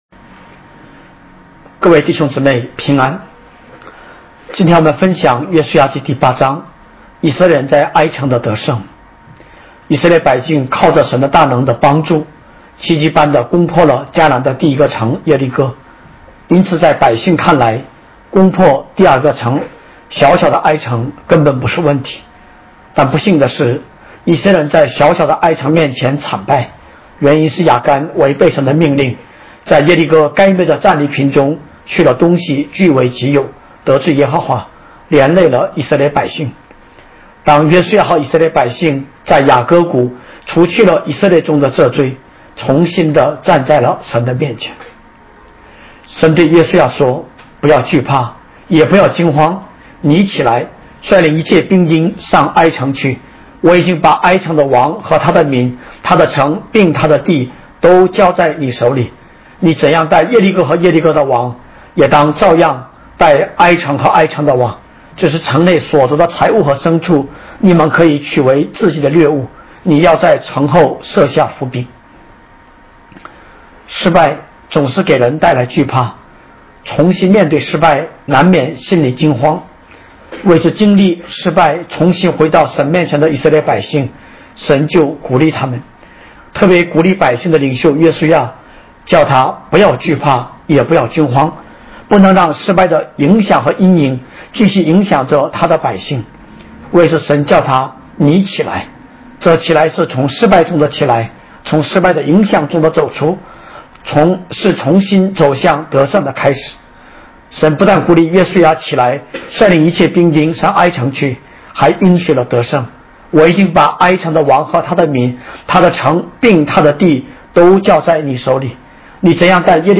讲道： 各位弟兄姊妹：平安！